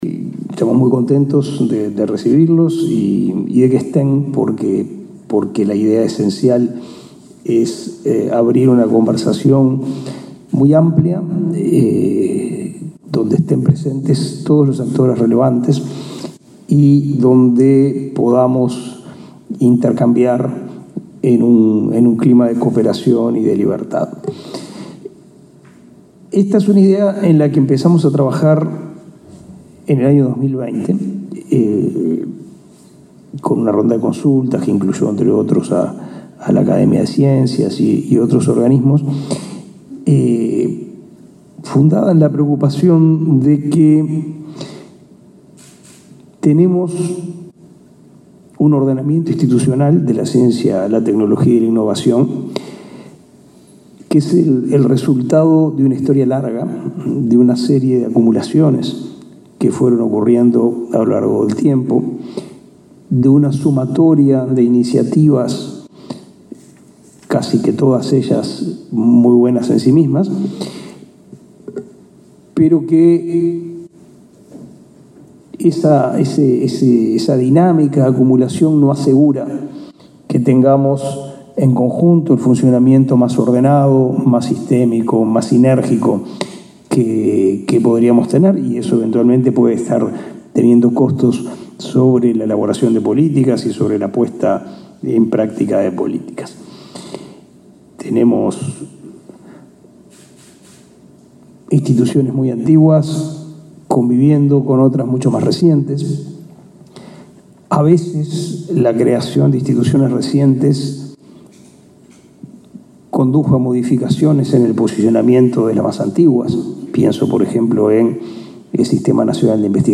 Palabras del ministro de Educación y Cultura, Pablo da Silveira
El ministro de Educación y Cultura, Pablo da Silveira, participó este jueves 1.° en Montevideo, de la presentación del proceso de reordenamiento